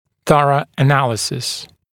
[‘θʌrə ə’næləsɪs][‘сарэ э’нэлэсис]тщательный, всесторонний анализ